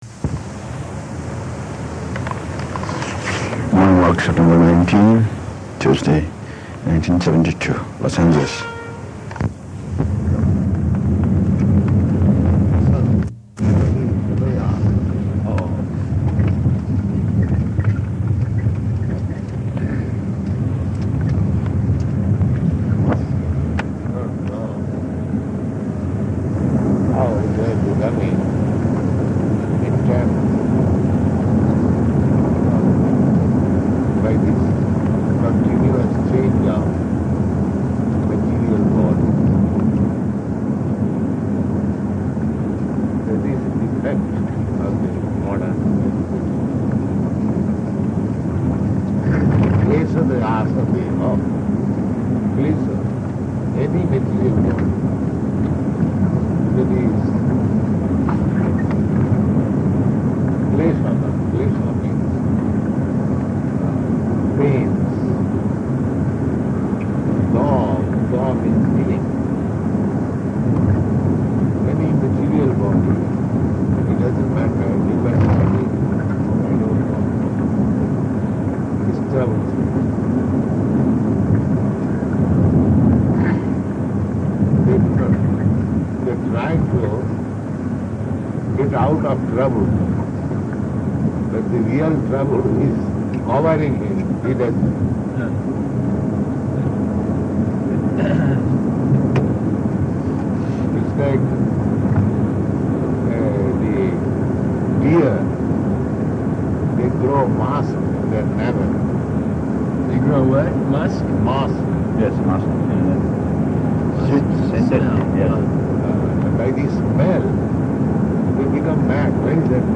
-- Type: Walk Dated: September 19th 1972 Location: Los Angeles Audio file